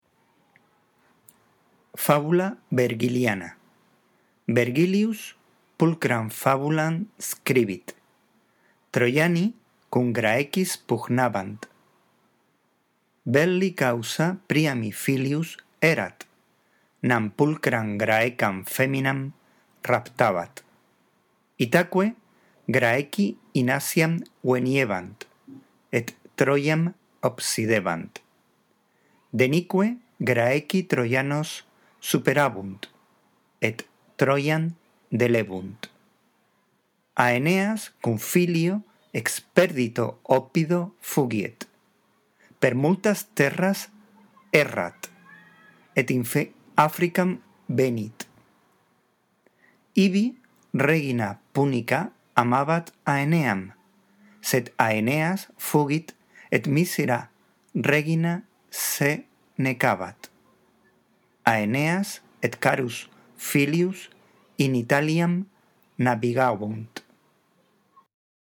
Lee, en primer lugar, despacio y en voz alta el texto en latín y no te olvides de respetar los signos de puntuación. La audición de este archivo te ayudará en la práctica de la lectura: